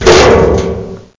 klonk6.mp3